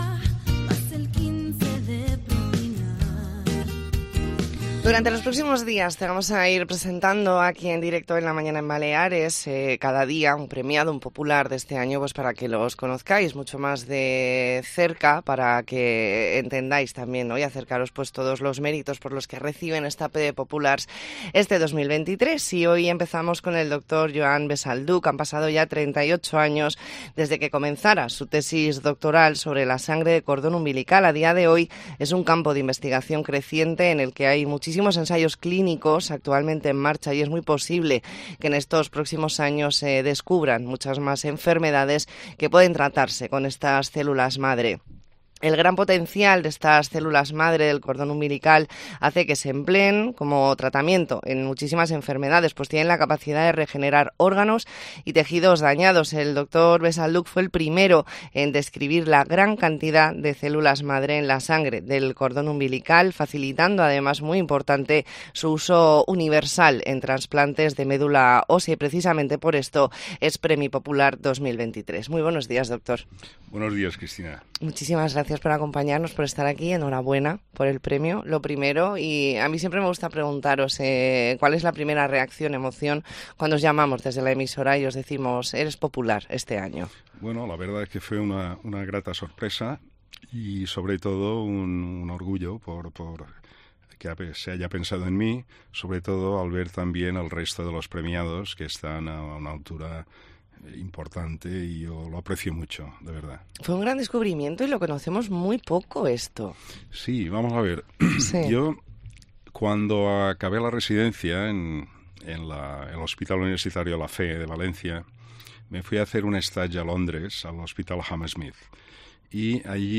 Entrevista en La Mañana en COPE Más Mallorca, lunes 20 de noviembre de 2023.